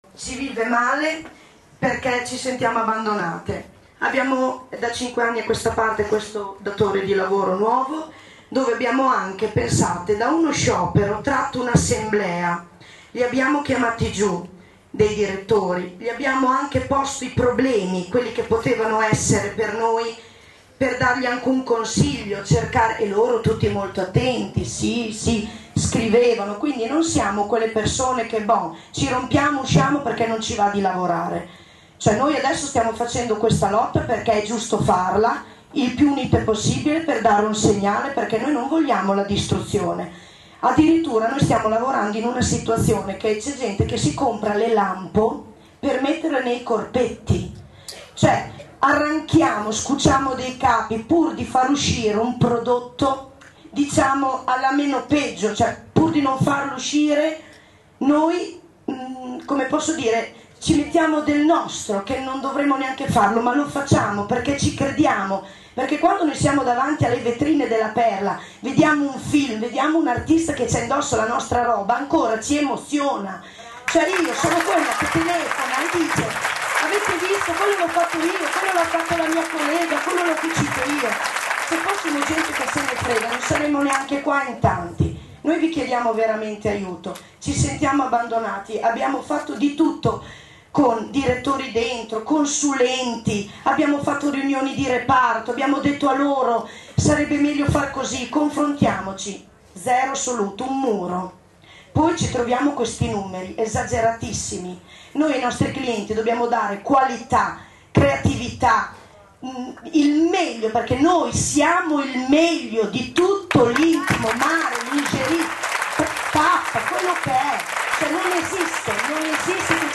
In assemblea anche una lavoratrice ha preso la parola, commuovendo con un discorso accorato e sentito, gran parte dei presenti.
La lavoratrice che commuove le istituzioni